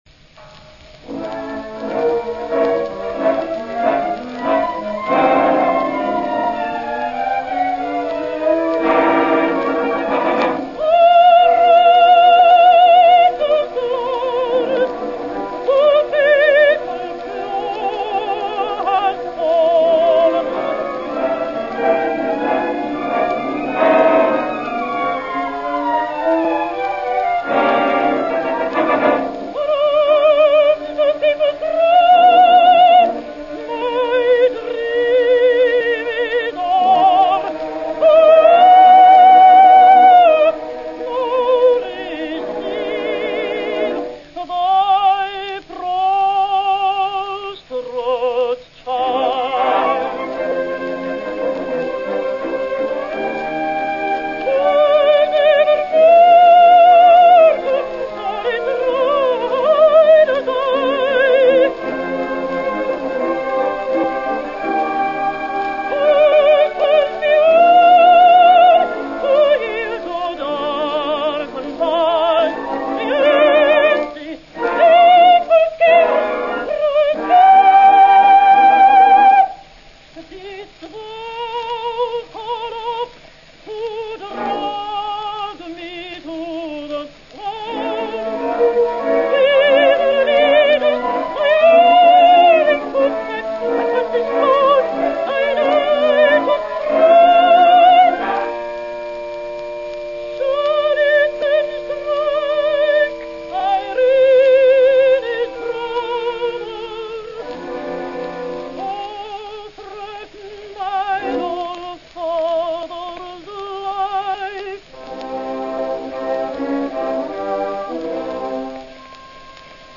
English contralto, 1873 - 1930
The most passionate singing comes from her recording as Adriano (Rienzi).